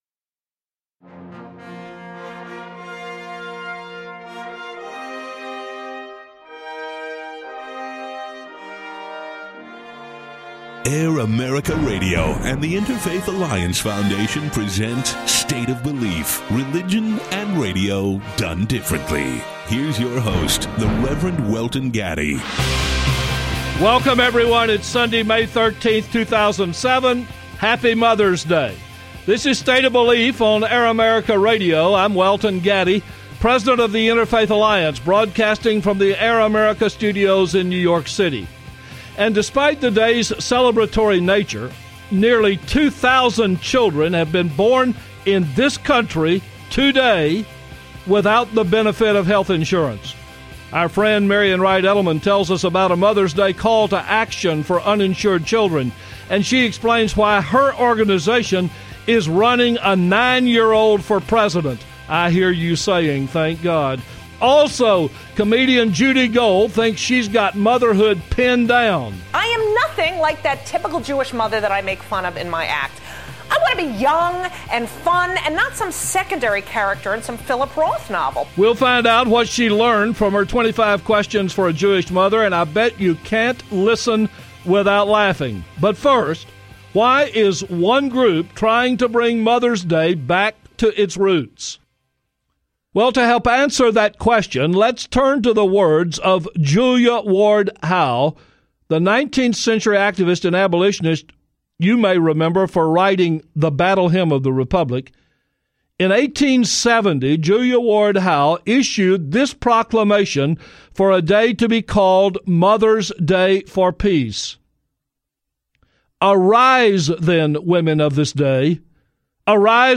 interviews the founder and CEO of the Children’s Defense Fund Marian Wright Edelman. She discusses the Children’s Defense Fund’s recent call to action for Mother’s Day to increase health care access for young children.